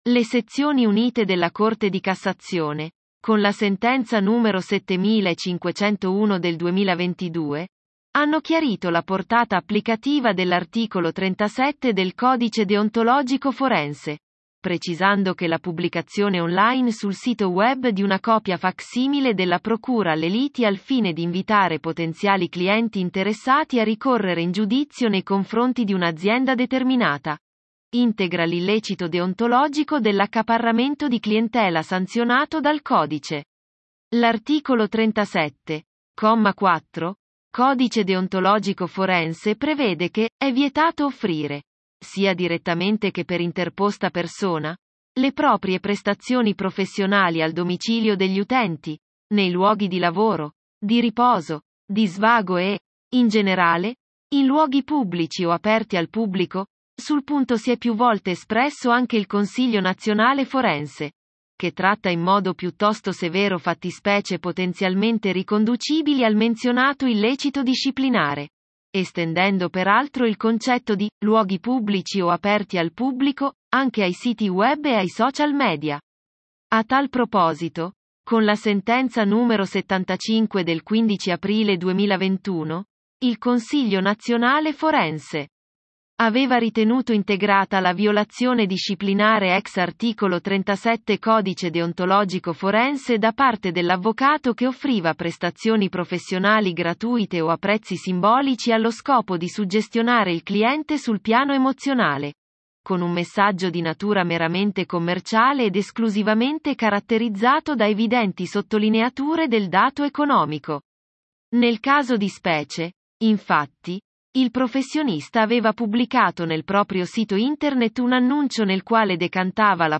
Ascolta la versione audio dell'articolo